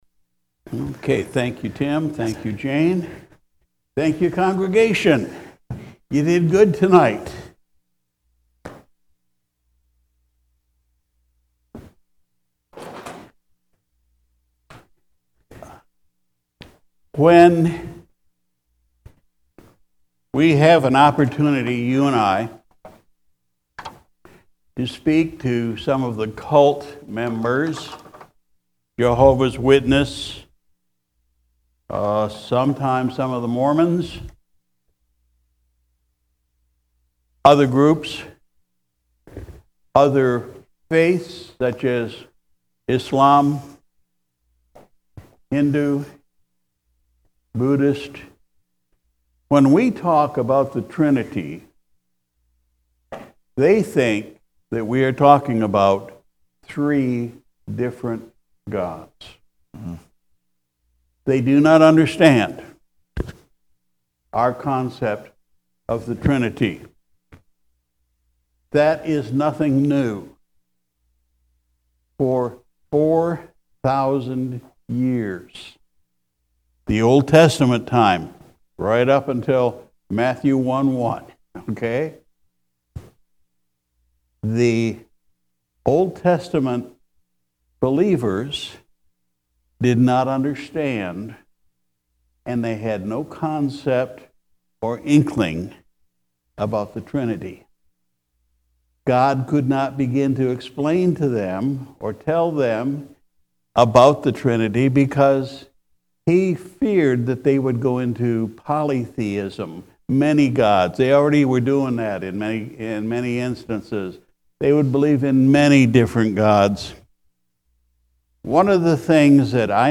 September 12 2021 Sunday Evening Service Pastor’s Message Title “The Trinity”
Sunday Evening Service